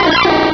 Cri de Qwilfish dans Pokémon Rubis et Saphir.